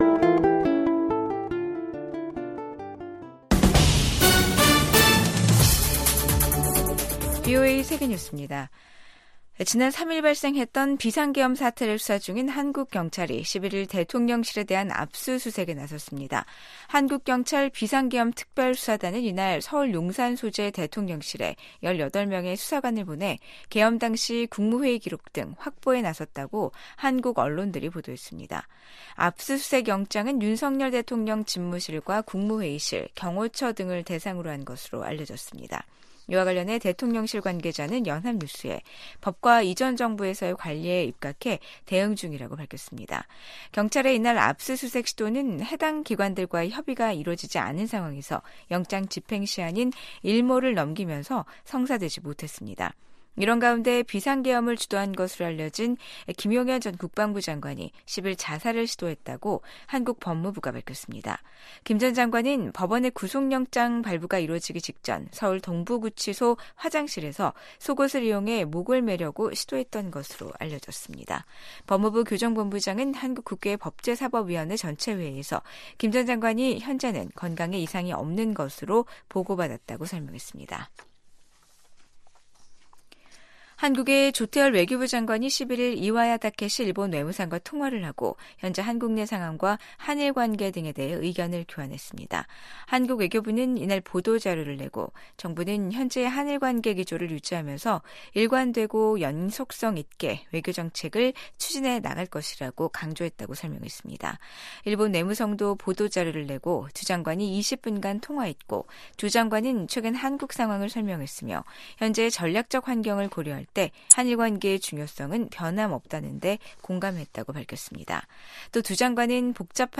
VOA 한국어 아침 뉴스 프로그램 '워싱턴 뉴스 광장'입니다. 12.3 비상계엄 사태를 수사하고 있는 한국 사법당국은 사건을 주도한 혐의를 받고 있는 김용현 전 국방부 장관을 구속했습니다. 한국의 비상계엄 사태 이후 한국 민주주의가 더욱 강해졌다고 미국 인권 전문가들이 평가했습니다. 윤석열 한국 대통령이 현 상황에서 효과적으로 한국을 통치할 수 있을지 자문해야 한다고 미국 민주당 소속 매릴린 스트릭랜드 하원의원이 밝혔습니다.